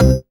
ORGAN-37.wav